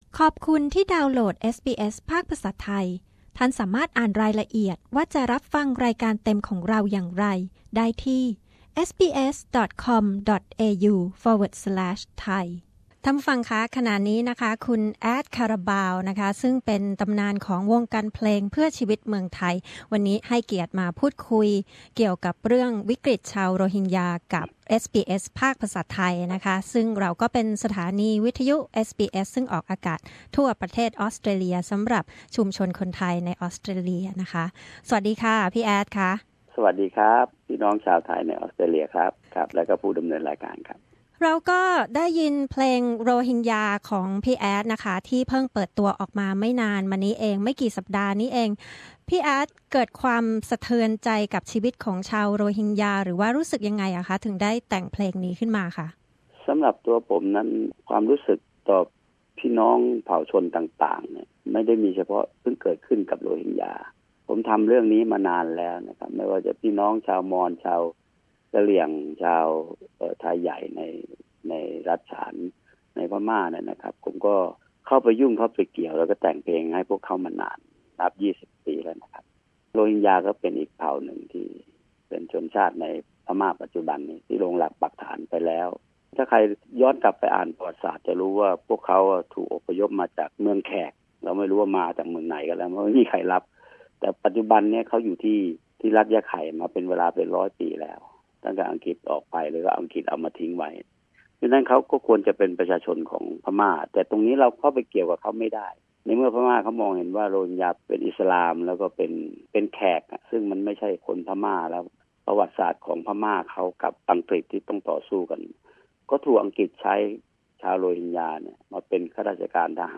Add Carabao, highly acclaimed Song for Life singer and songwriter, talked exclusively to SBS Thai about Rohingya crisis. Recently released his latest song depicting the desperate life of Rohingya refugees among a blast of anti Rohingya sentiments hitting the social media in Thailand, he urges Thais to have some sympathy for this most prosecuted and marginalized minority.